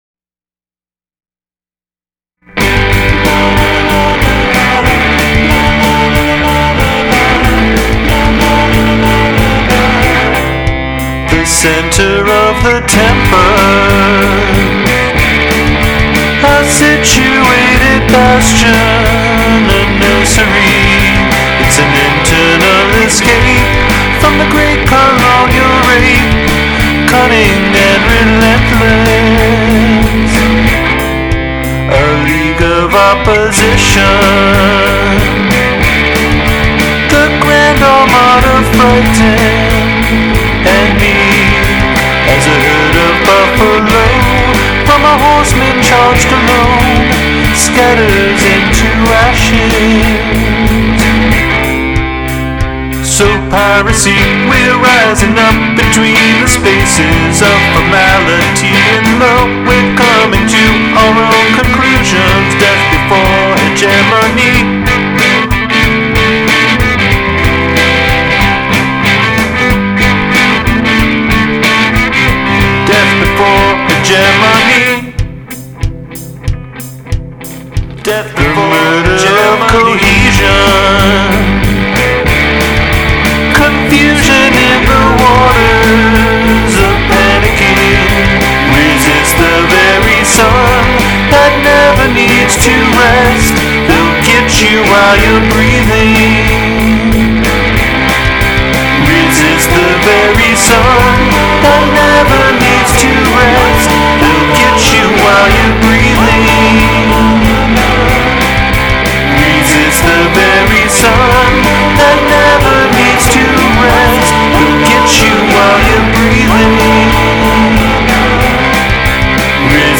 Additional Vocals